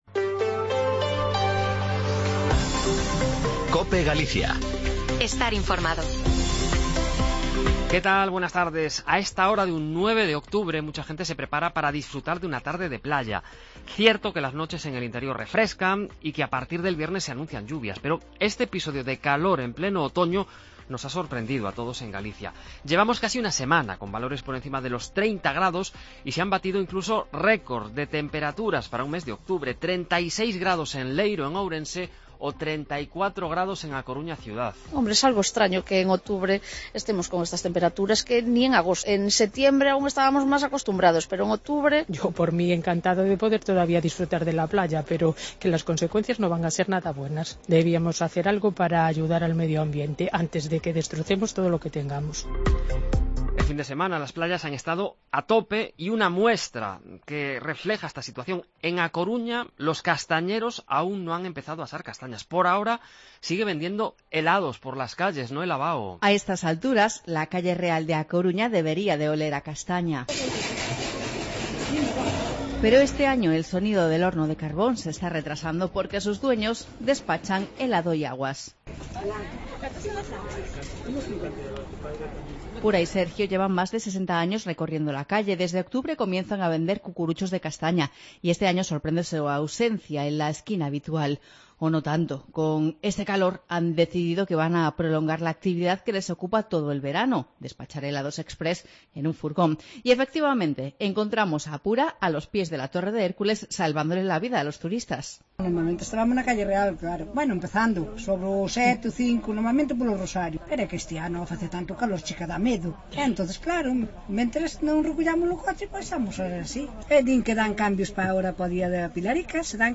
"Yo encantada de poder ir a la playa, pero esto va a tener consecuencias" lamenta una mujer en Cope Ferrol.